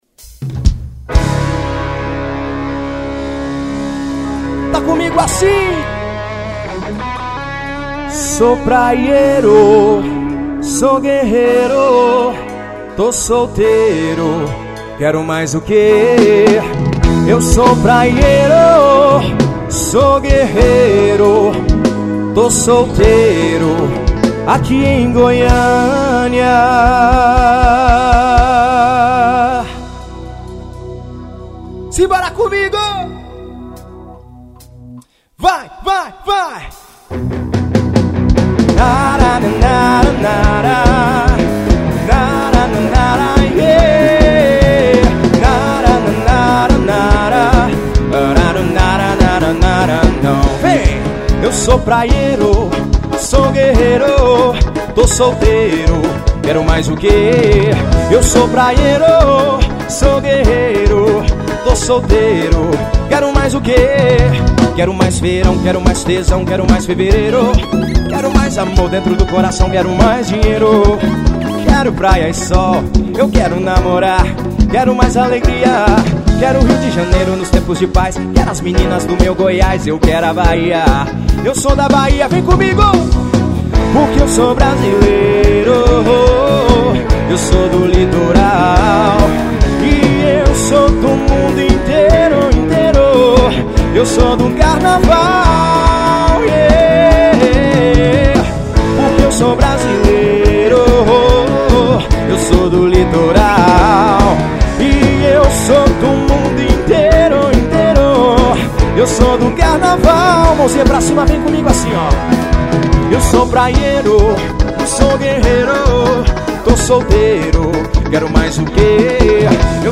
Ao Vivo e Goiania.